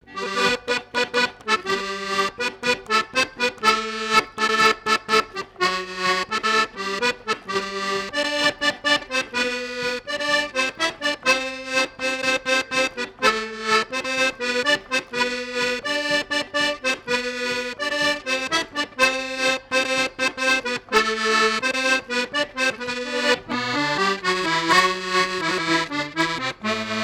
Genre strophique
Fête de l'accordéon
Pièce musicale inédite